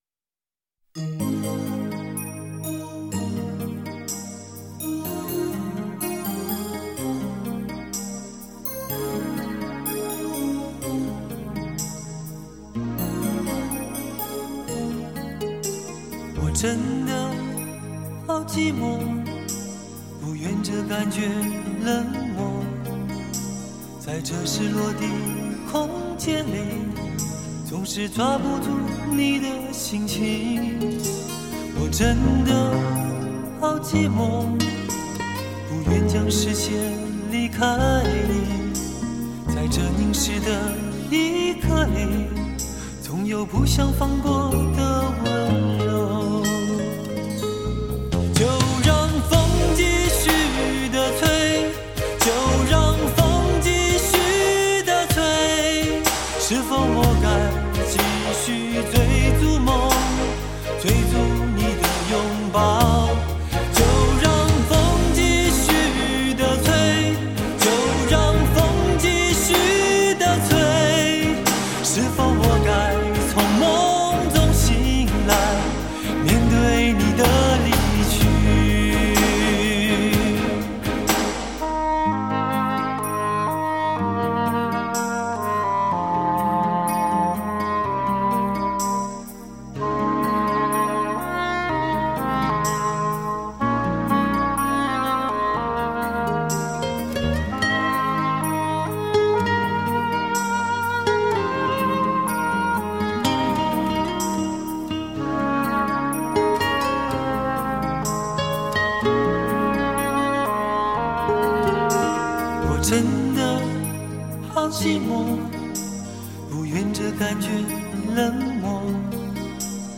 台湾男歌手